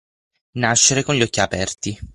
/aˈpɛr.ti/